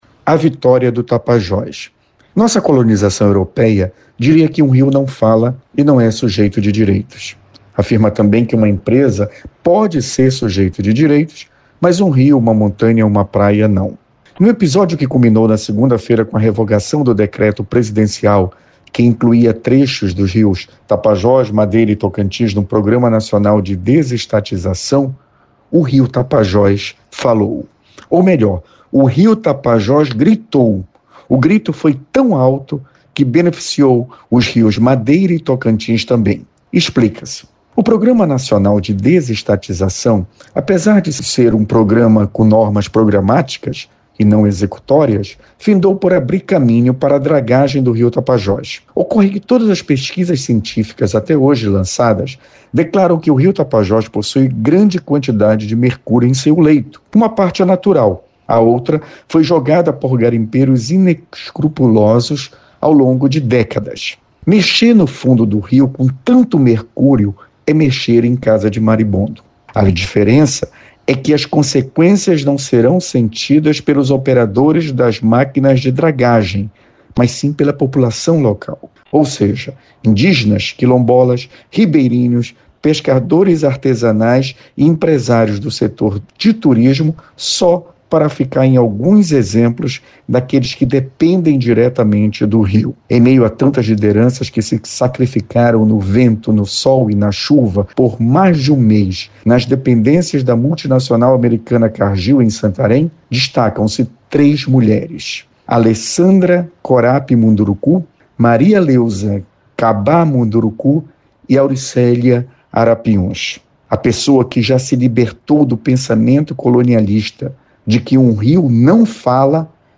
Confira na íntegra o editorial com Procurador Regional da República, Felício Pontes